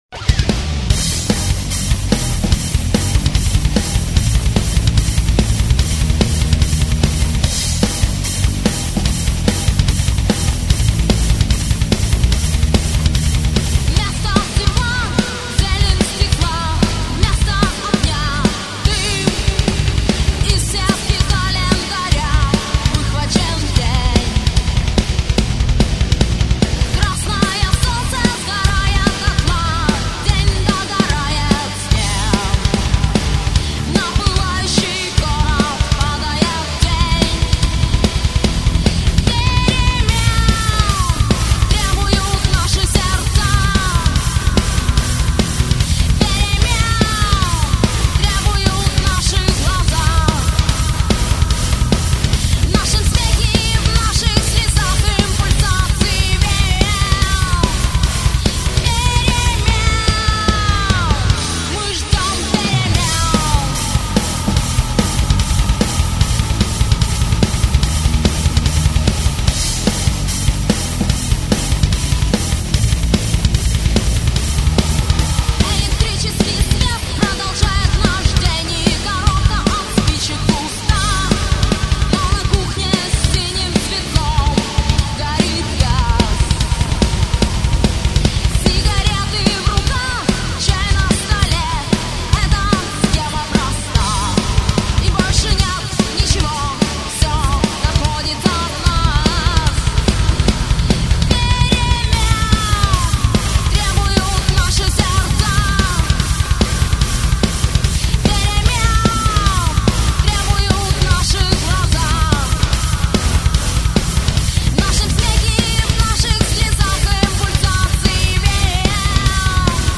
Gothic Metal Nu Metal Heavy Metal